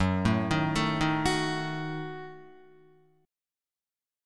F#m6 chord